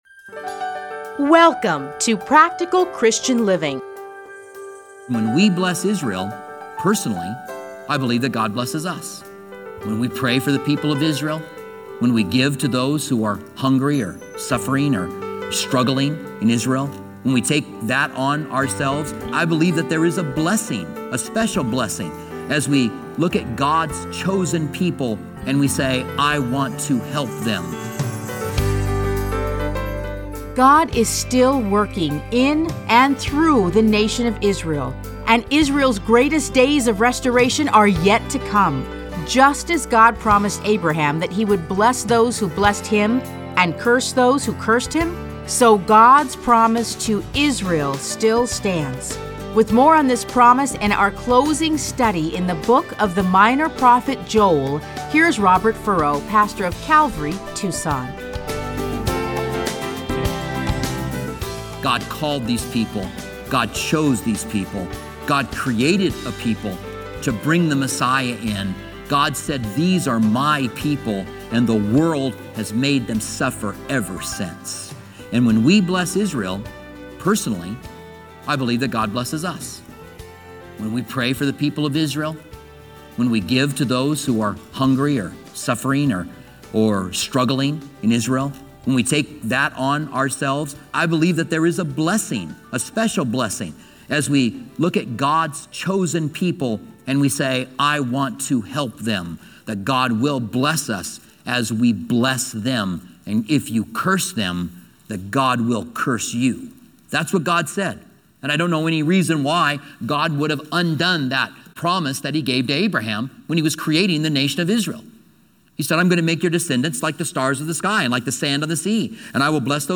Listen to a teaching from Joel 3:1-21.